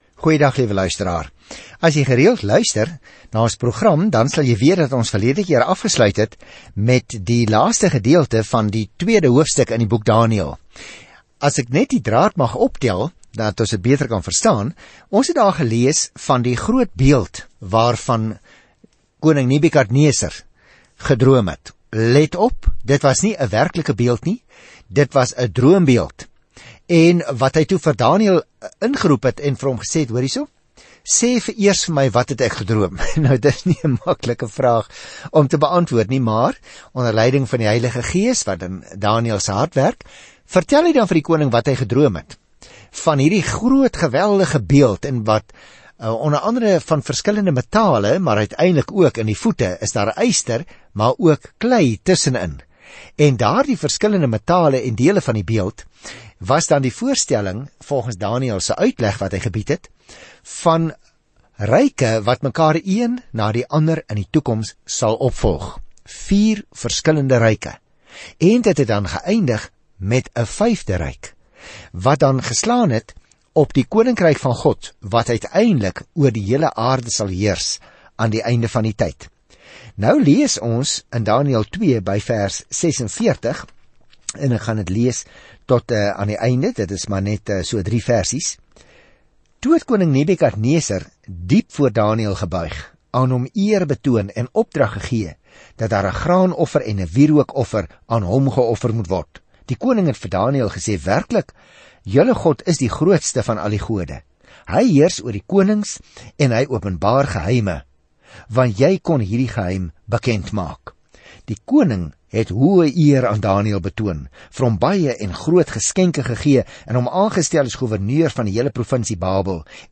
Skrif DANIËL 2:46-49 DANIËL 3:1-18 Dag 4 Begin met hierdie leesplan Dag 6 Aangaande hierdie leesplan Die boek Daniël is beide 'n biografie van 'n man wat God geglo het en 'n profetiese visie van wie uiteindelik die wêreld sal regeer. Reis daagliks deur Daniël terwyl jy na die oudiostudie luister en uitgesoekte verse uit God se woord lees.